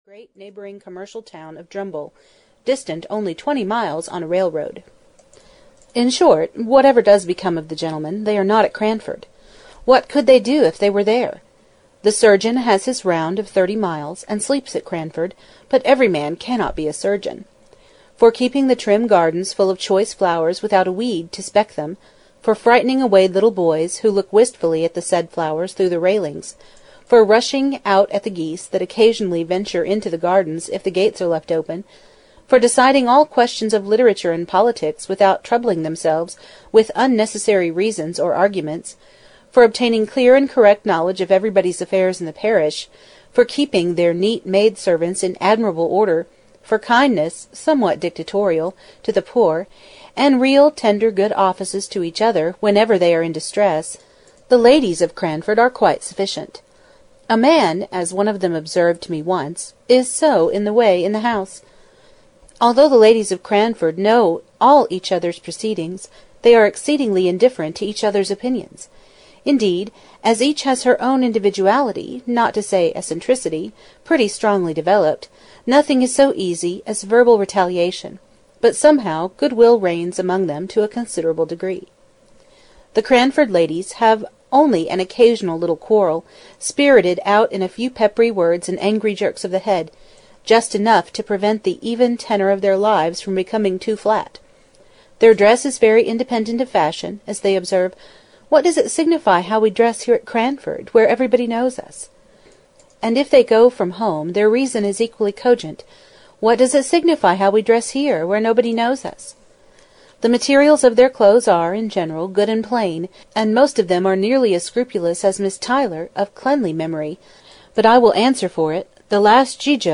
Cranford (EN) audiokniha
Ukázka z knihy